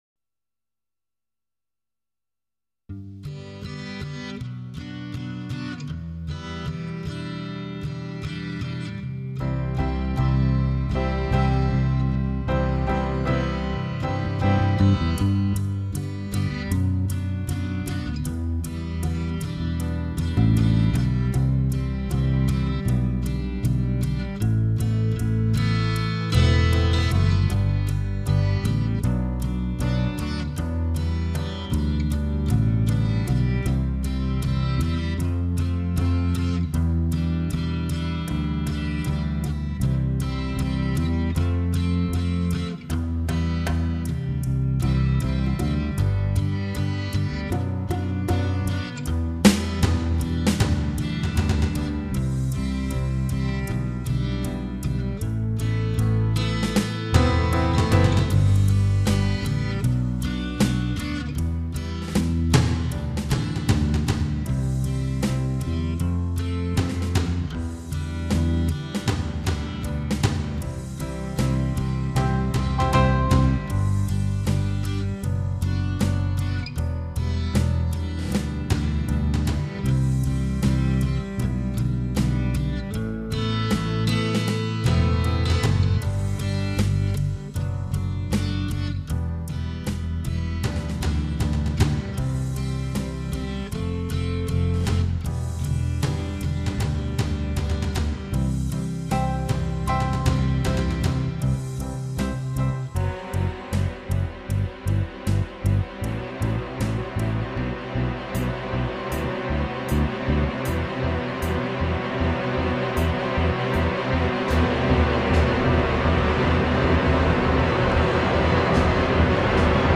минусовка версия 181117